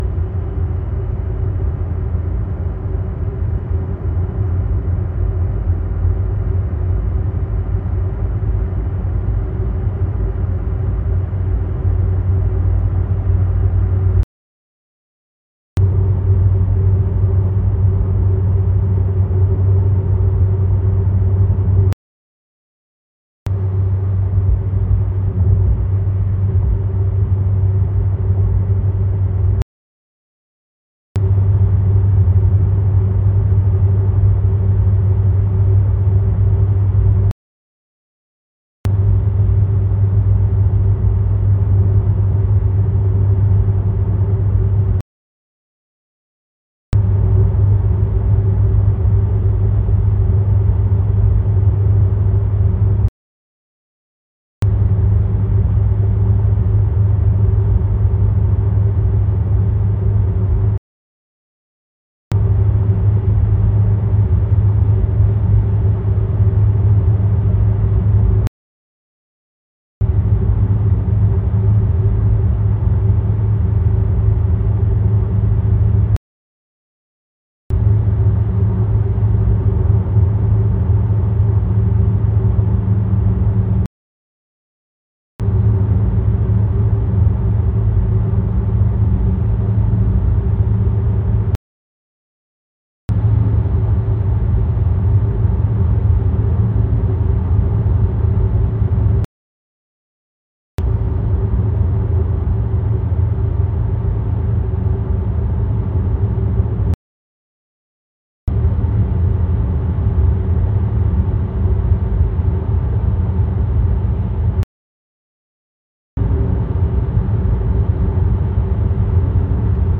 Nachfolgend mal ein Versuch das Brummgeräusch vom #1 Brabus aufzunehmen.
Aufnahme vom Fahrersitz aus, je Ohr eine Aufnahmekapsel.
Das Brummgeräusch baut sich zum Ende des ersten Blocks (0 - 13 Sek.) nach und nach auf. Die anschließenden Geschwindigkeiten werden dann schrittweise konstant gefahren. Bitte mit den bestmöglichen Kopfhörern wiedergeben, da es sich um binaurale Tonaufnahmen handelt. Die Pausen wurden absichtlich in die Aufnahme eingefügt, damit sich das Gehör leichter umstellen kann. 0 - 13 Sek.
Smart_Brummen.mp3